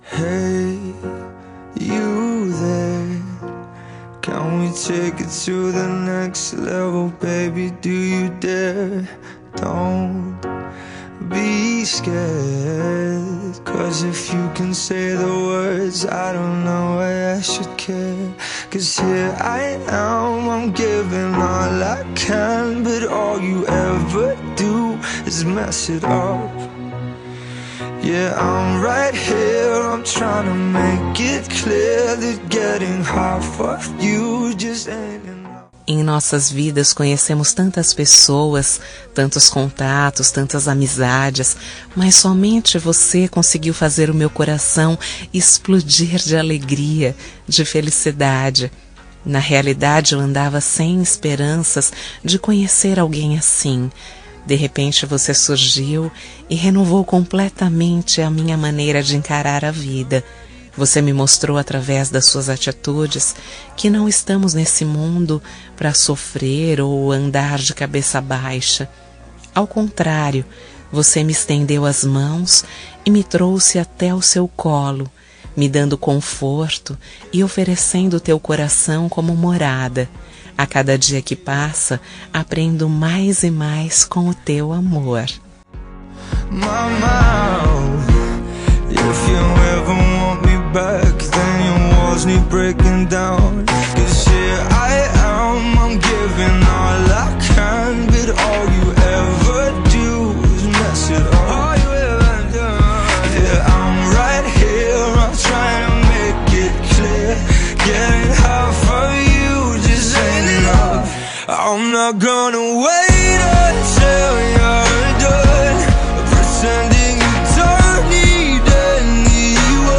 Telemensagem Romântica para Marido – Voz Feminina – Cód: 9067